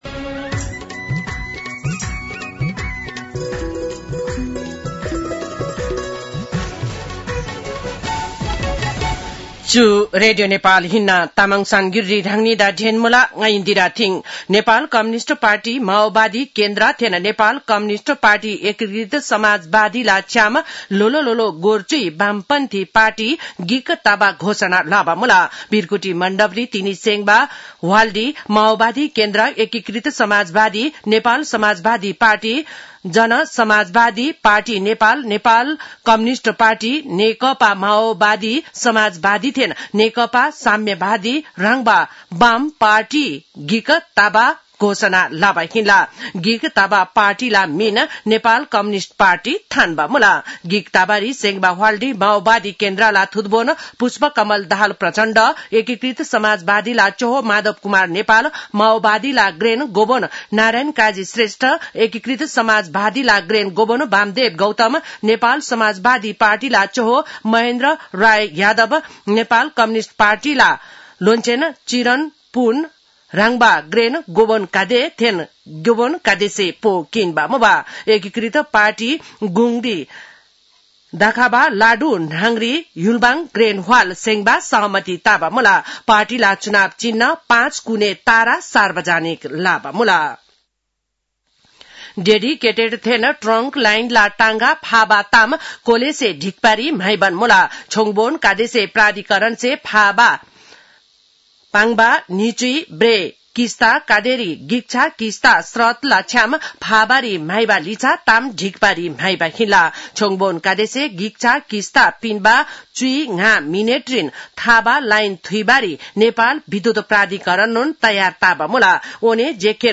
तामाङ भाषाको समाचार : १९ कार्तिक , २०८२